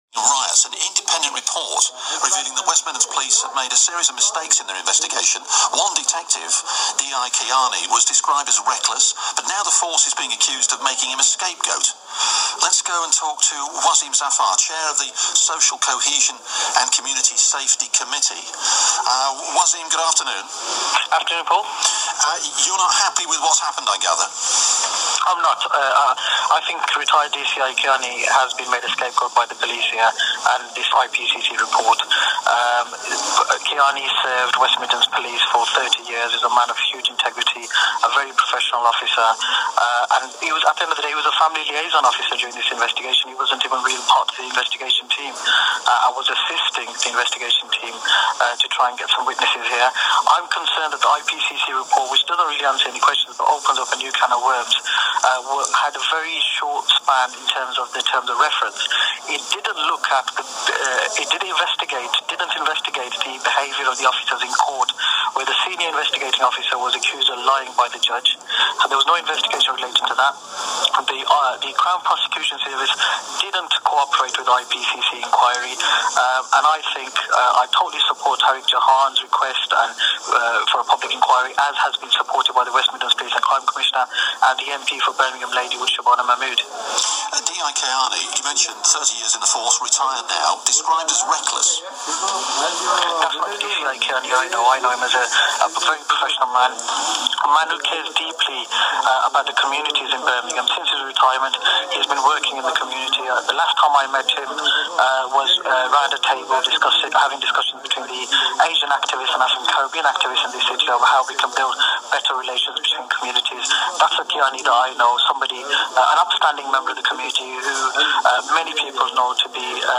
Waseem being interviewed by BBCWM